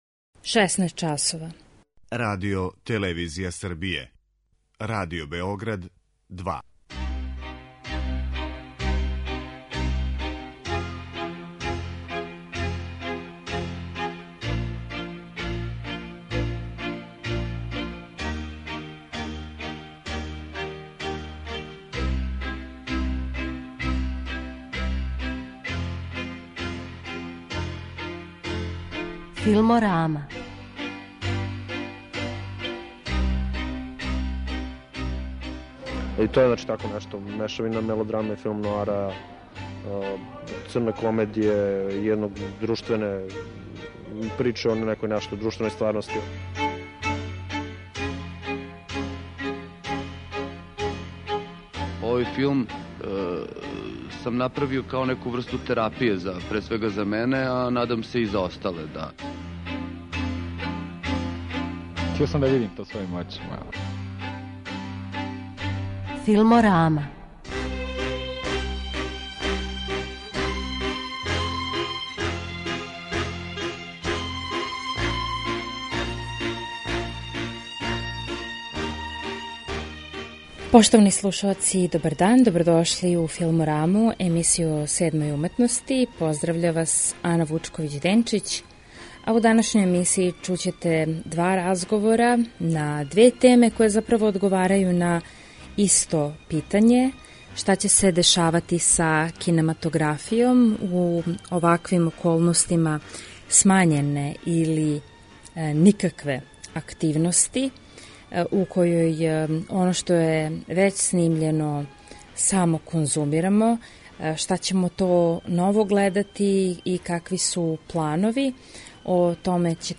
Емисија о седмој уметности